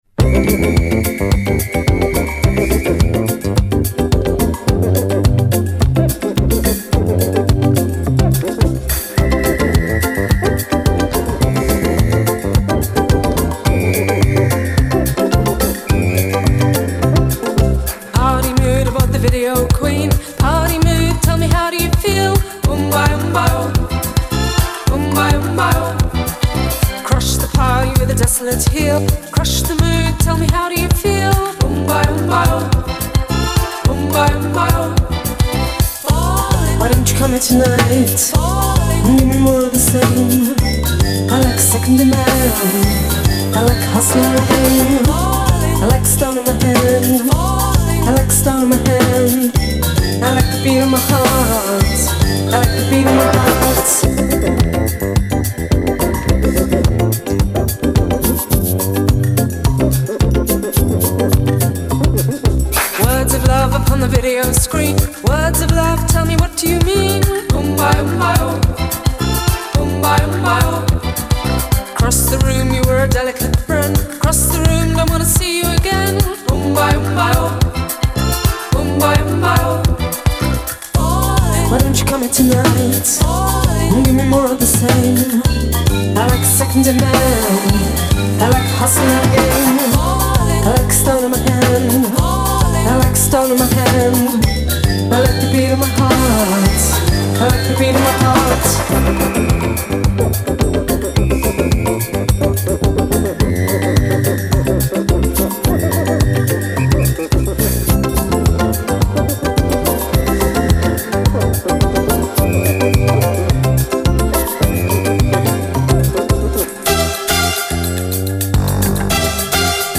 Genre: Synthpop.